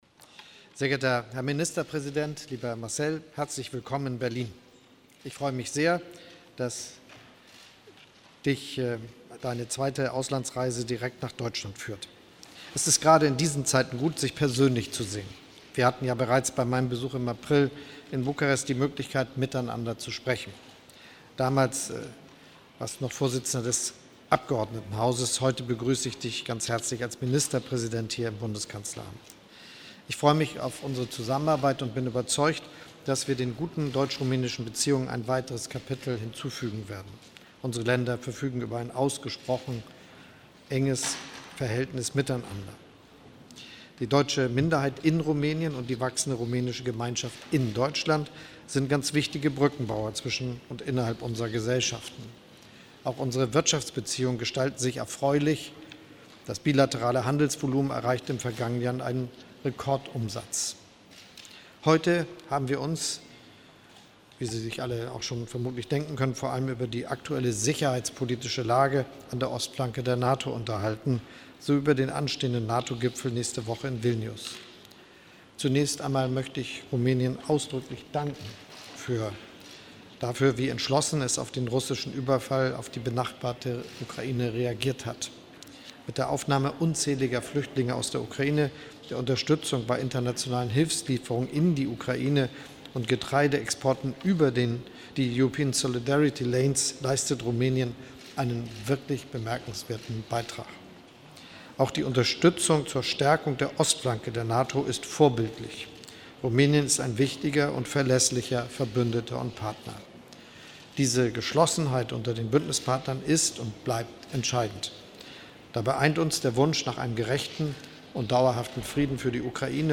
Pressekonferenz von Kanzler Scholz und dem Ministerpräsidenten Rumäniens Ciolacu